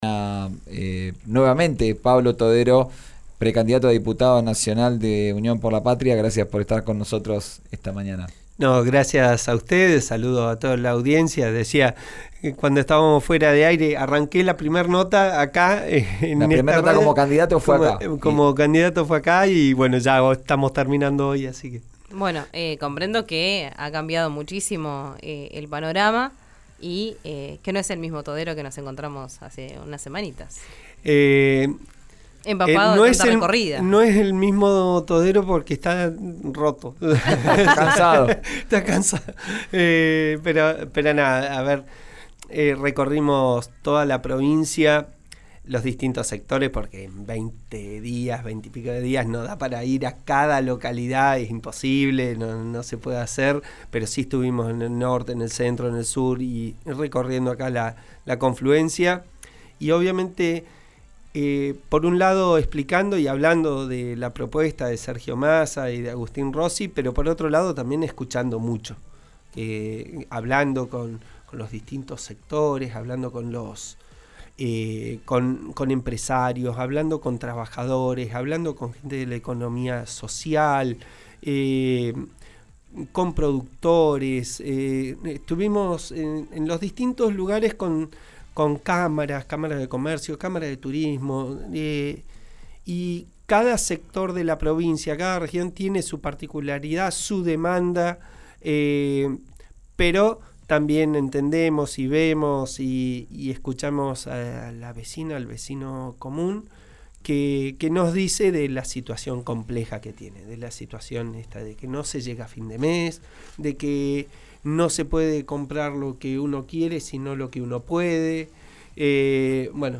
El precandidato a diputado nacional visitó hoy los estudios de RÍO NEGRO RADIO a horas de cerrar la campaña para las PASO del domingo.